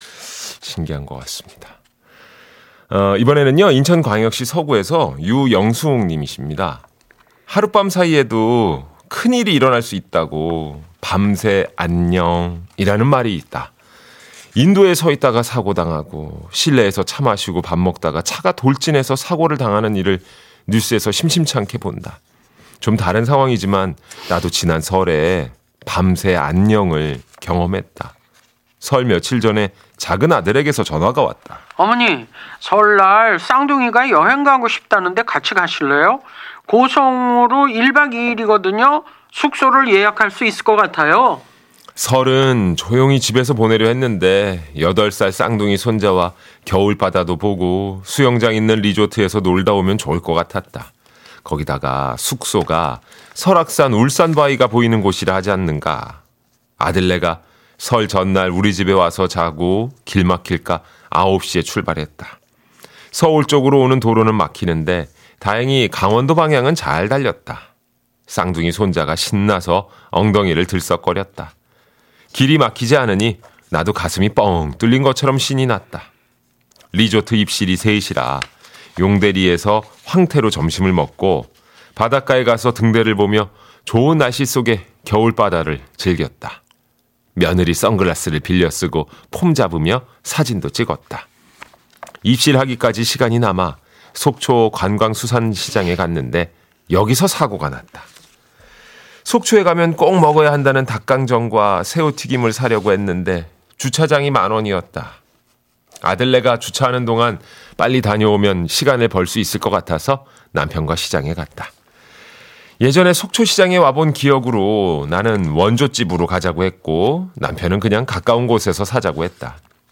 양희은과 김일중 두 분이 읽어주시는 사연은 글로 읽는 것과 다르게 정말 맛깔스럽게 느껴졌다. 현장이 생생하게 살아났다.
*MBC 라디오 여성시대(3월 9일) 방송분을 쌍둥이 아빠가 다운로드해 주었습니다 .